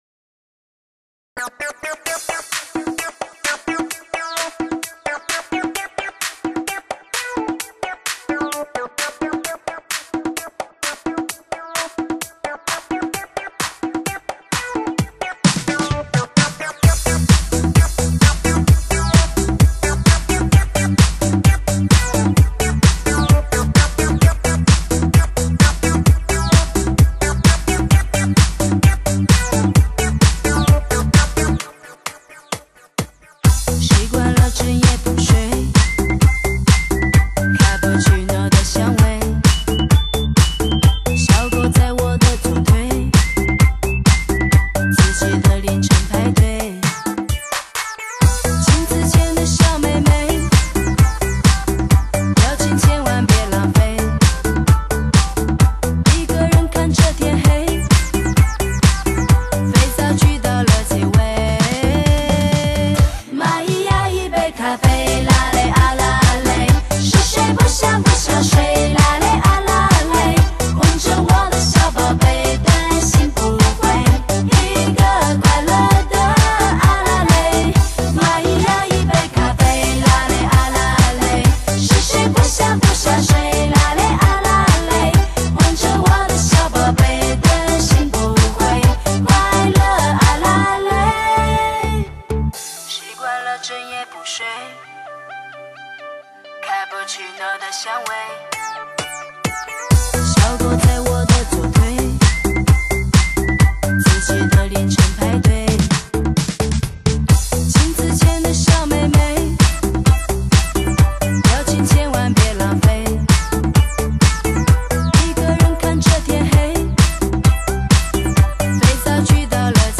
获得权威电音杂志DJ Mangazine高度好评 全国各大Pub场必打之碟